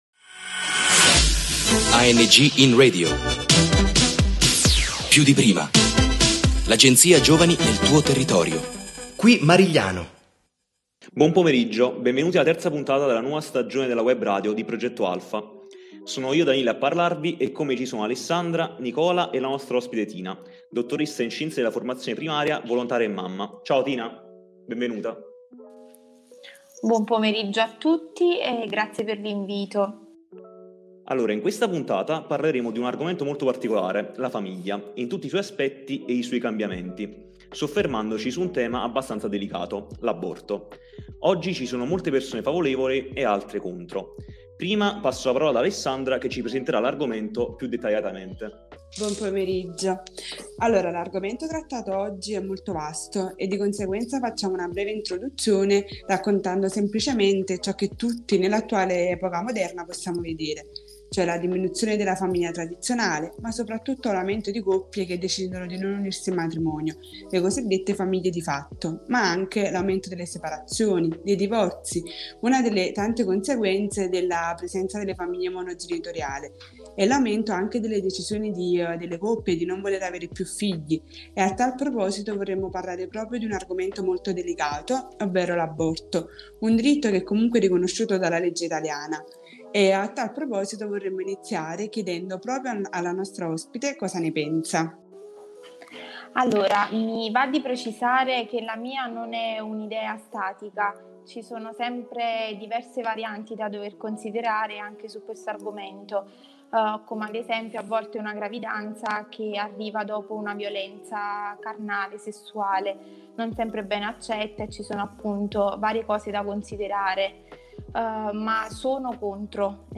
28 Nov ANGinRadio – Marigliano – Giovani a confronto su un tema delicato: l’aborto Inserito alle 22:53h in ANGinRadio#piùdiprima da VOLONTARIO IN SERVIZIO CIVILE In questa nuova puntata della webradio di Progetto Alfa i nostri giovani si sono confrontati sul tema delicato dell’aborto. In un dialogo appassionato si sono palesate diverse concezioni e visioni.